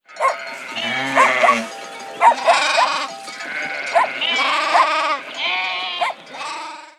Les sons vont au-delà des simples cris des animaux. Ils restituent une ambiance.